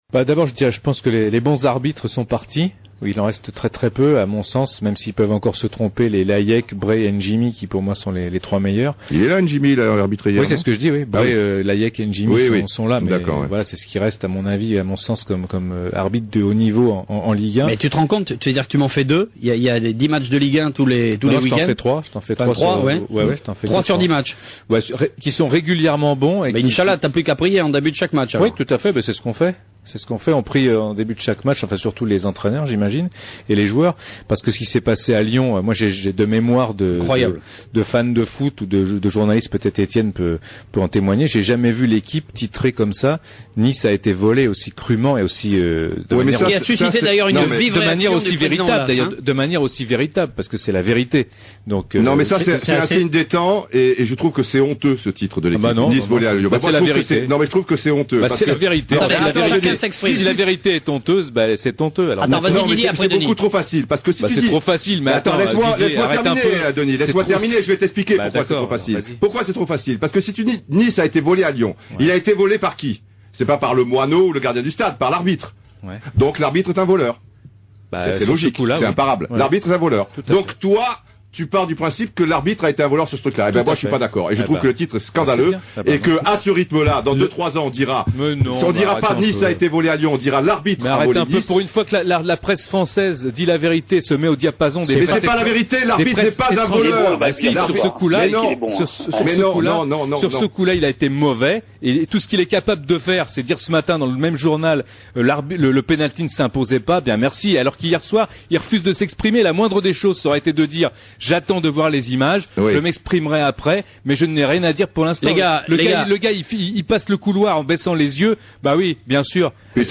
Confronté à Denis Balbir et d’autres spécialistes de l’arbitrage, Didier Roustan a bravement livré bataille…
Après sa bonne performance lors de l’Euro sur W9, nous devons de nouveau lui taper affectueusement dans le dos pour sa saine interpellation de ses confrères dans le studio d’Europe Foot, sur Europe 1 lundi soir [15 septembre].